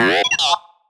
sci-fi_driod_robot_emote_neg_07.wav